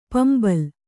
♪ pambal